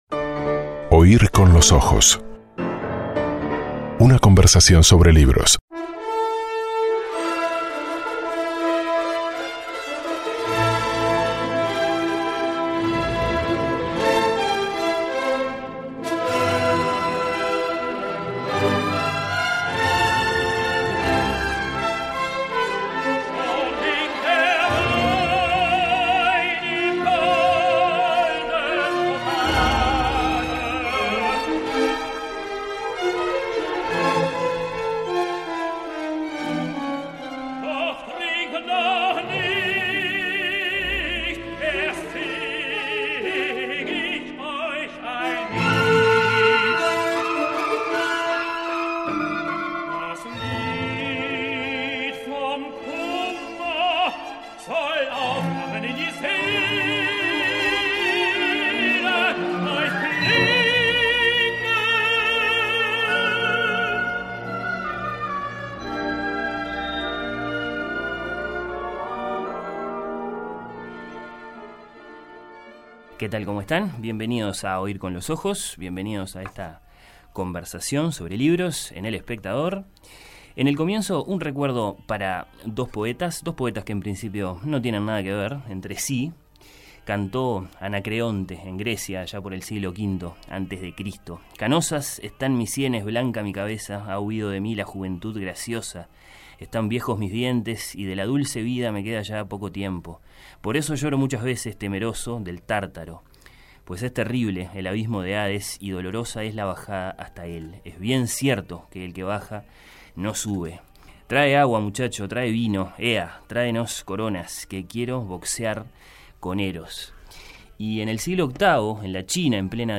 En esta edición de Oír con los ojos se escuchó Der Trunkene im Frühling - "El borracho en primavera";, de Mahler sobre un poema de Li Bai, por Jonas Kaufmann y la Filarmónica de Berlín, dirigida por Claudio Abbado.